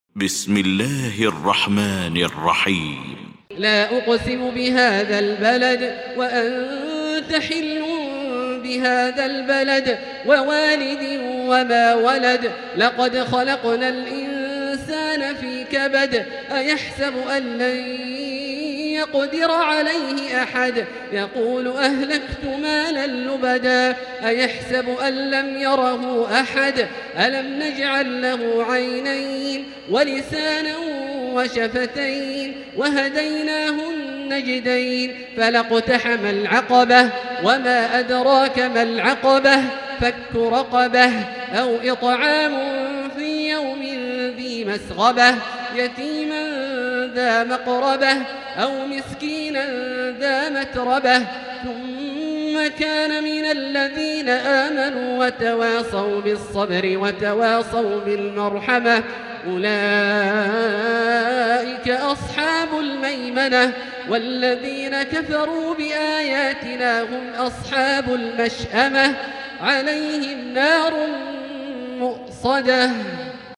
المكان: المسجد الحرام الشيخ: فضيلة الشيخ عبدالله الجهني فضيلة الشيخ عبدالله الجهني البلد